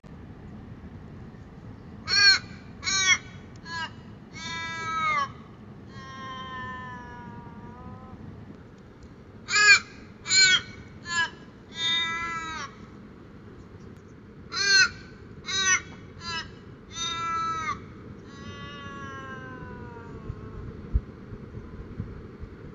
Eine australische "Jammerkrähe", jedenfalls nennen wir sie so wegen ihrer Rufe.